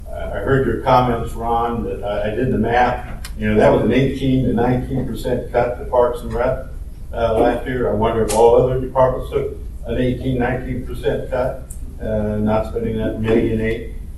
A standing-room only crowd came out Monday for a public forum on the future of Parks and Recreation programming, held at Manhattan Fire HQ.